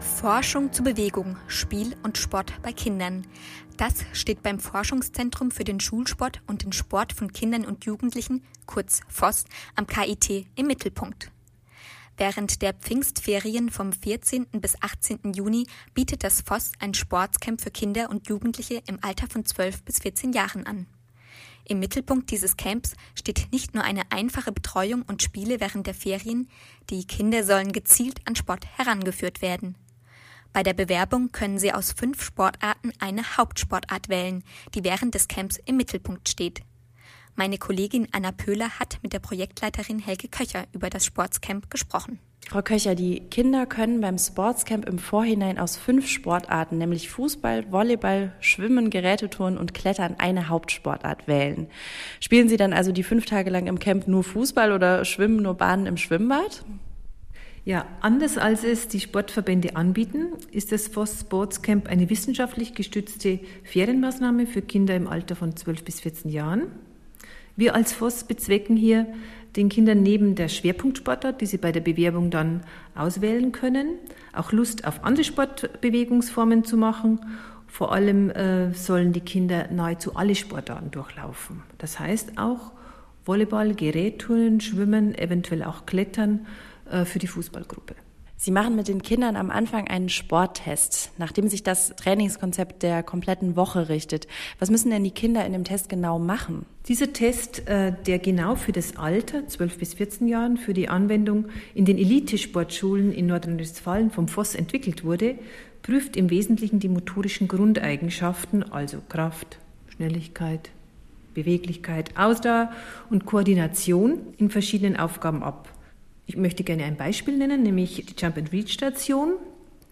Sport für die Nintendo-Generation : das SportsCamp 2011 des Forschungszentrums für den Schulsport ; Beitrag bei Radio KIT am 24.03.2011